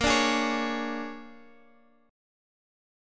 Bb7#9 chord